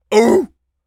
seal_walrus_hurt_01.wav